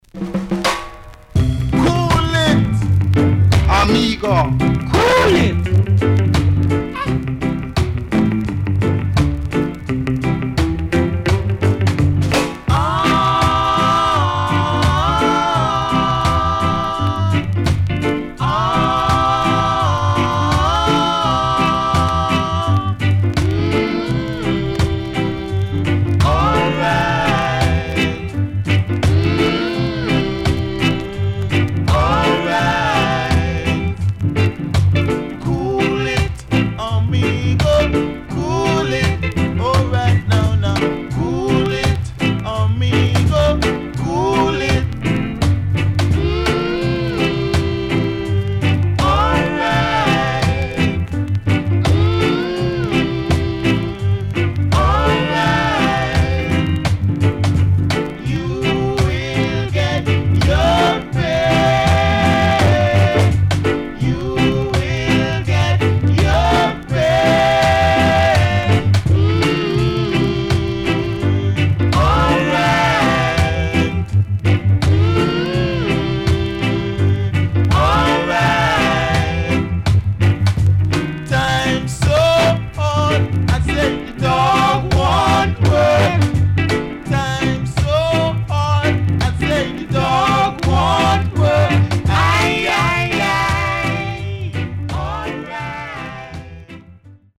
Horn Inst & Rocksteady
SIDE A:所々チリノイズがあり、少しプチノイズ入ります。